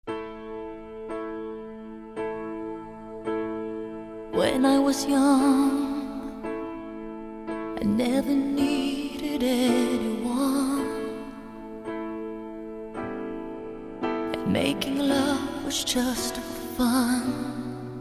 ПОП-СОПРАНО.